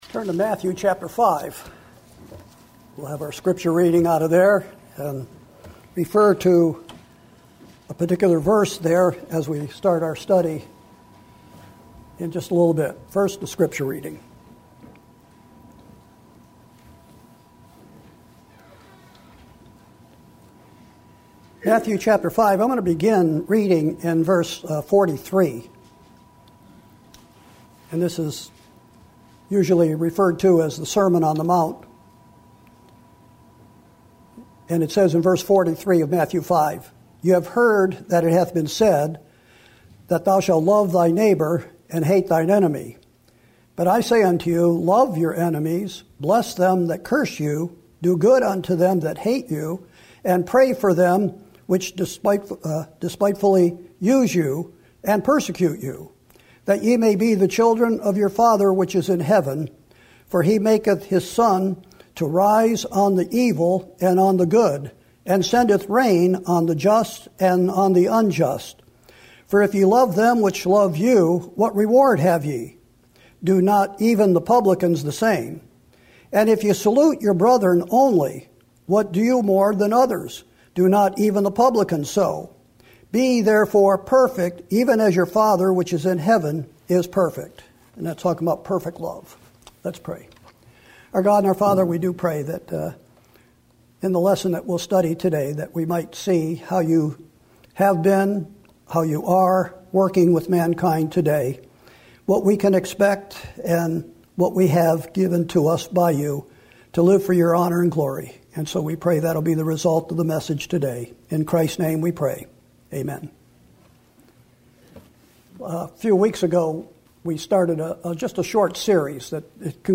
Sermons & Single Studies / Sunday 11am